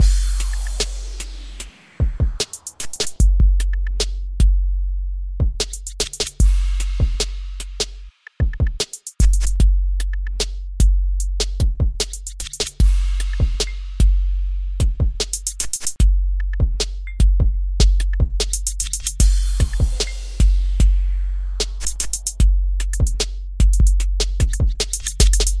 描述：75BPm，轻微压缩
Tag: 75 bpm Hip Hop Loops Drum Loops 4.31 MB wav Key : Unknown